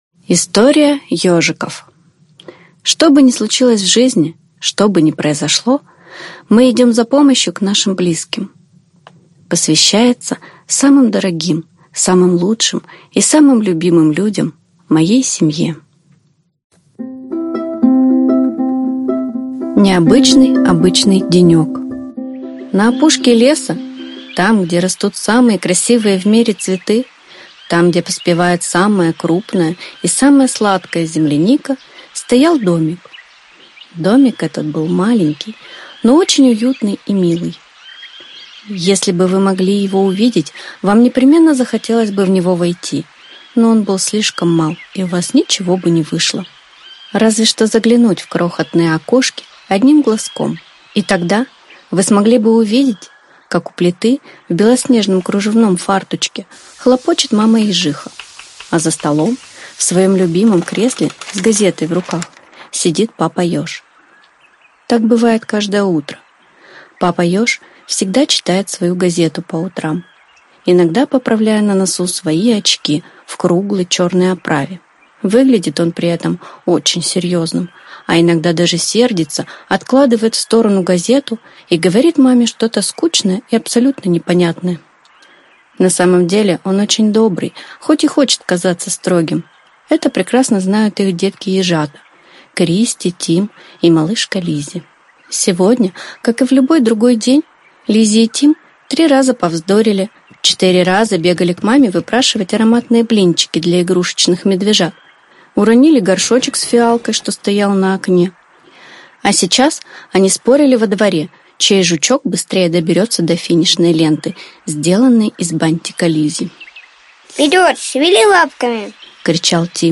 Аудиокнига История Ёжиков | Библиотека аудиокниг